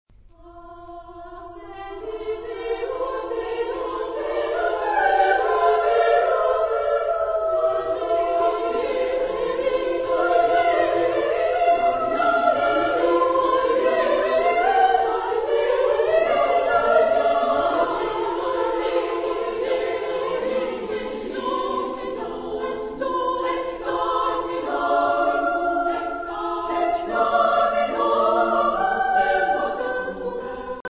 Genre-Style-Forme : Motet ; Sacré
Type de choeur : SSSAA  (5 voix égales de femmes )
Tonalité : fa majeur
interprété par Cantando cantabile
Réf. discographique : 7. Deutscher Chorwettbewerb 2006 Kiel